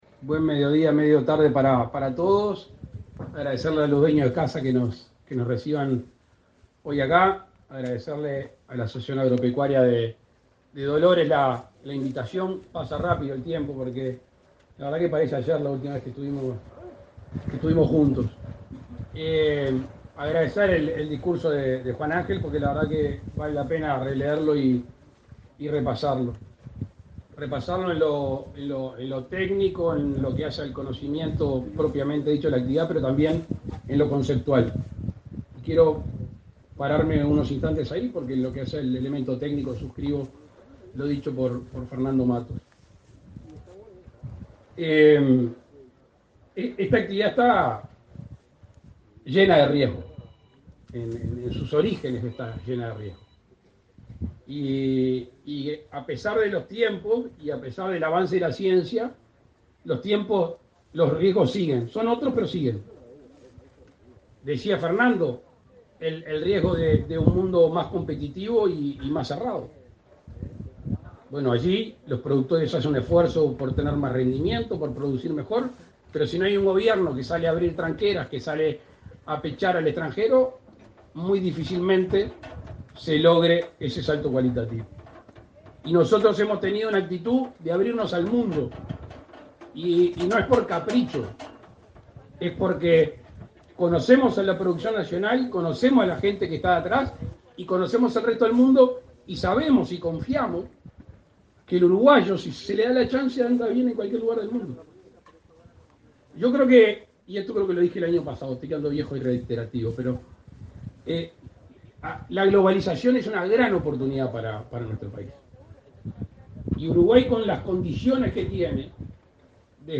Palabras del presidente de la República, Luis Lacalle Pou, en Dolores
Con la presencia del presidente de la República, Luis Lacalle Pou, se realizó, este 15 de noviembre, la inauguración de la cosecha de trigo en la